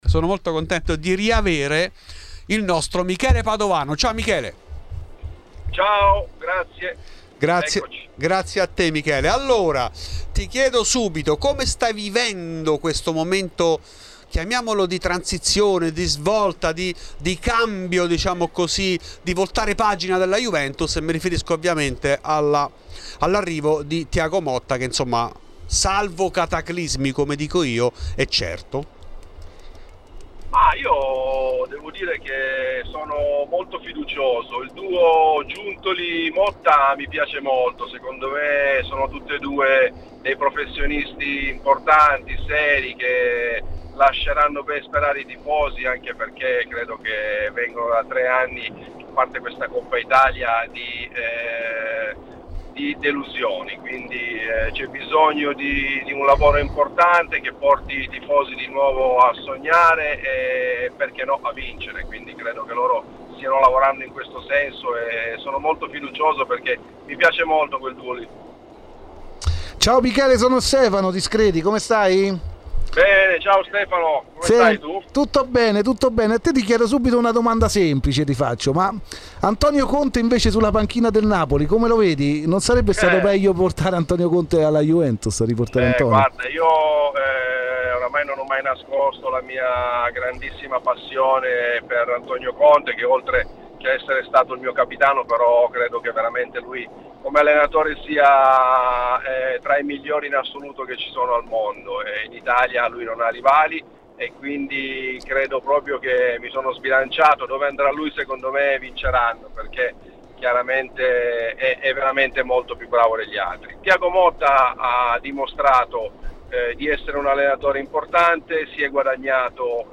In ESCLUSIVA a Fuori di Juve Michele Padovano. L'ex attaccante bianconero parla anche del possibile ritorno di Chiellini tra i quadri dirigenziali.